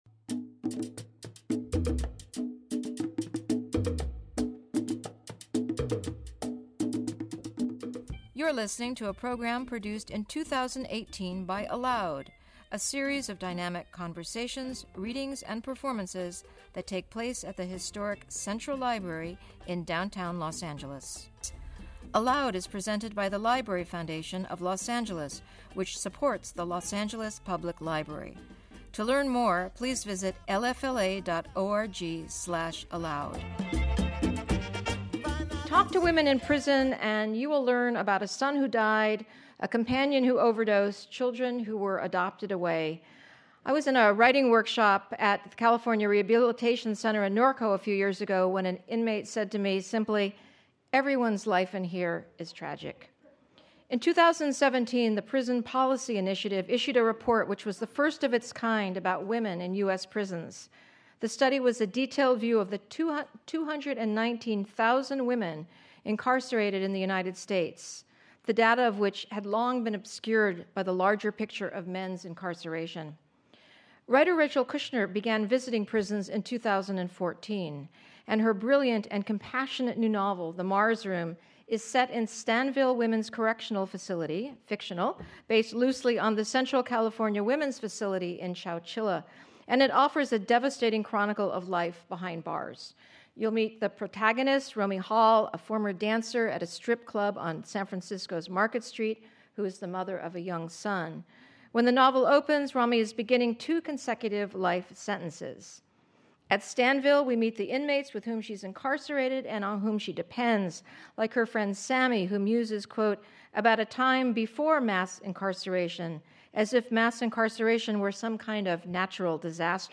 Rachel Kushner In conversation with Danzy Senna